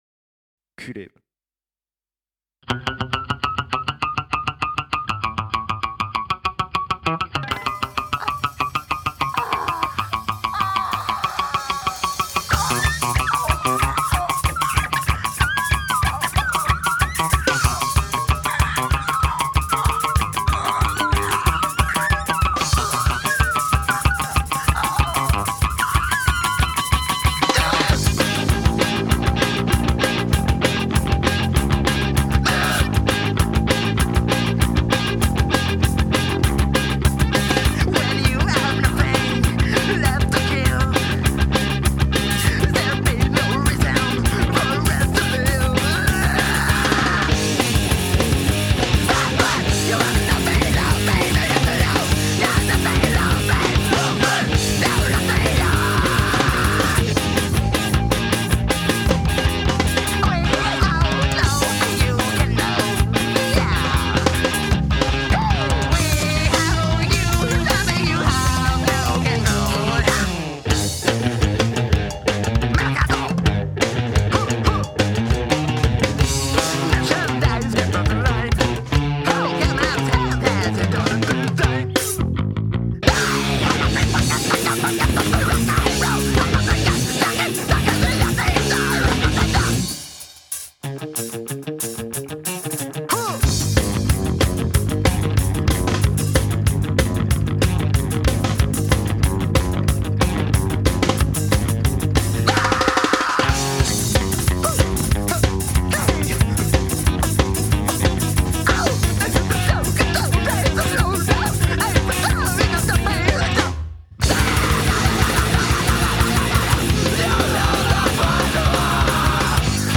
pour une unique session analogique